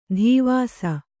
♪ dhīvāsa